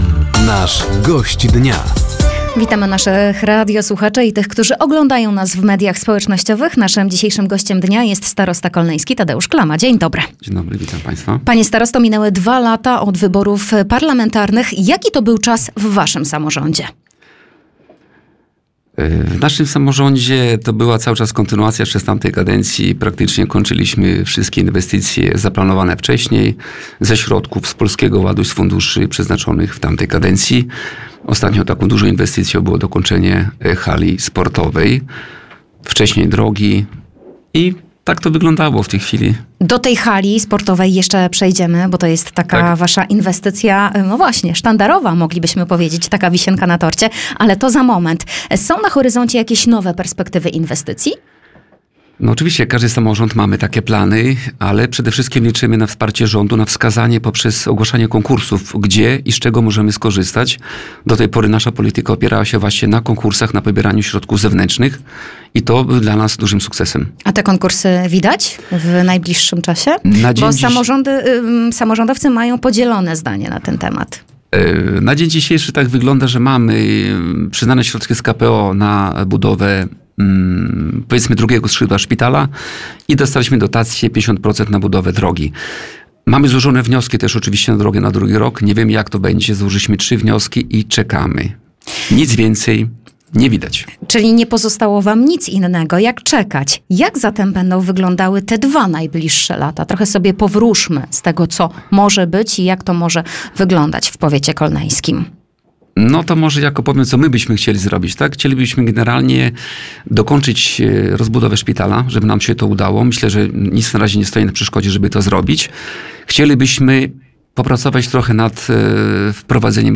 Mijające dwa lata od wyborów parlamentarnych, inwestycje w szpitalu, a także środki z Programu Ochrony Ludności i Obrony Cywilnej – to główne tematy rozmowy z Gościem Dnia Radia Nadzieja, którym był Tadeusz Klama, Starosta Kolneński.